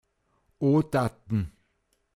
pinzgauer mundart
Odatn, m. Großvater